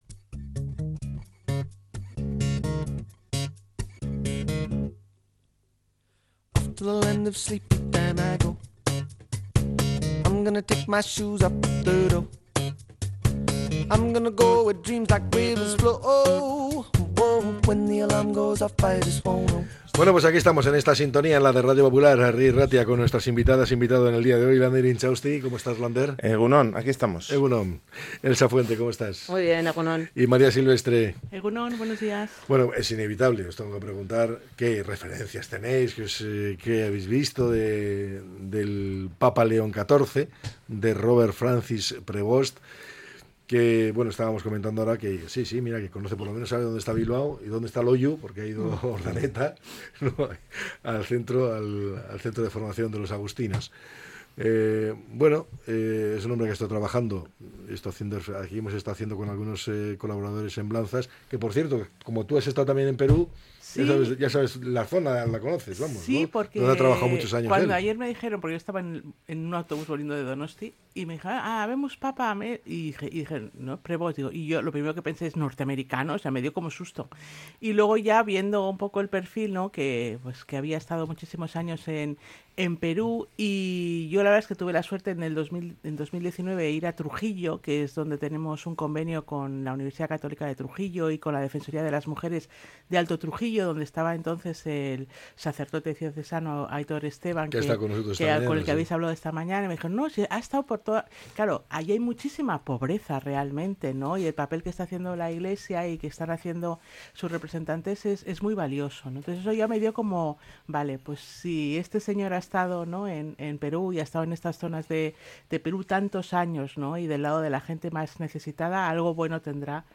La tertulia 09-05-25.